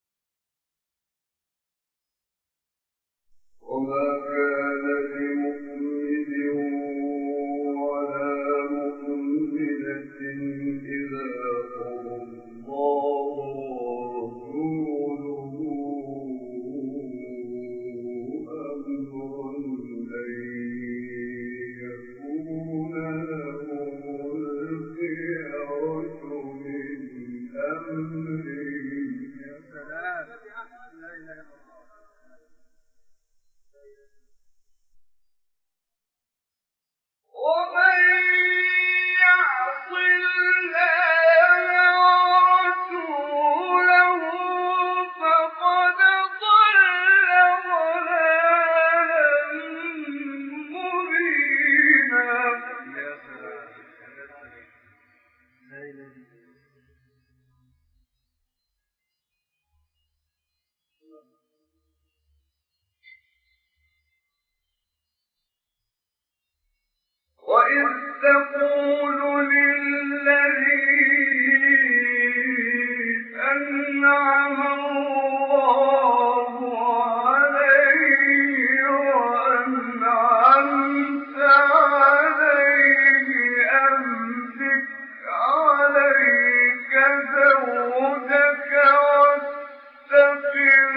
مقام النهاوند ( تلاوة ) محمد عمران ـ الاحزاب - لحفظ الملف في مجلد خاص اضغط بالزر الأيمن هنا ثم اختر (حفظ الهدف باسم - Save Target As) واختر المكان المناسب